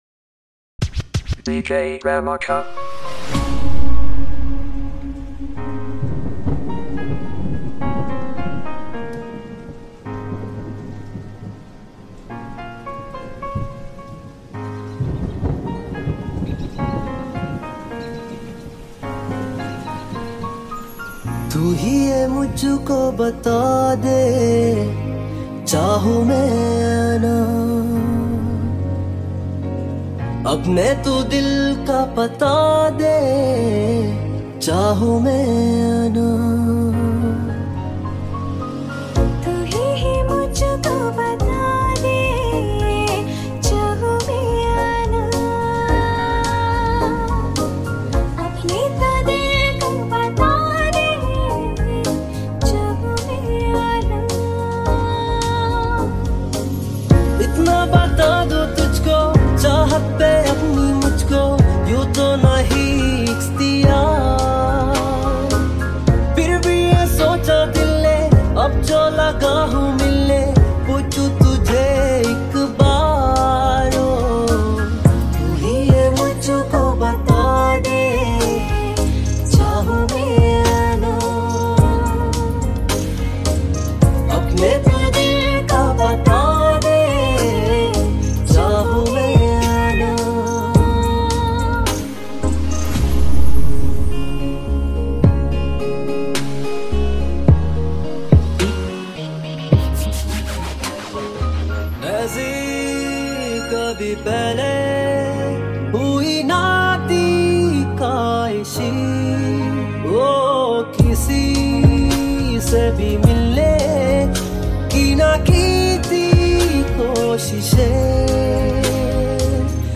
Chillout Remix
Cover song